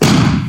touched.wav